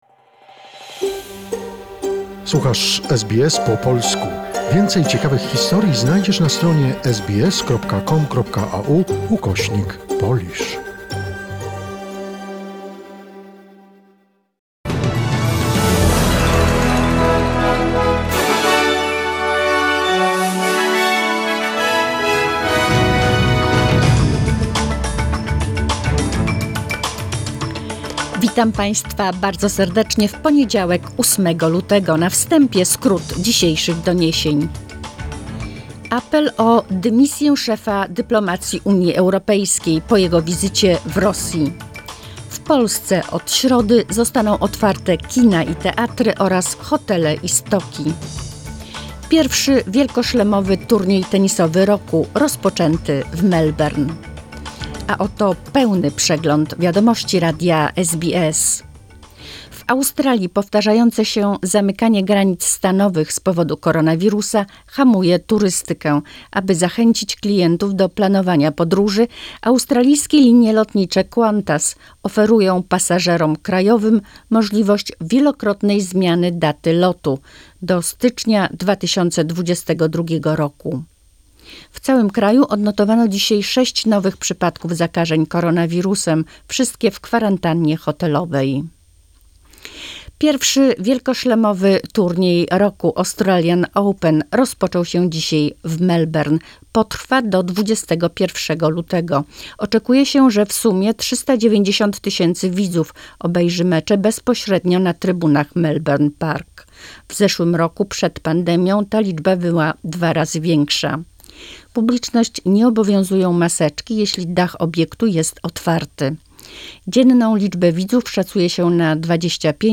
Wiadomości SBS, 8 luty 2021 r.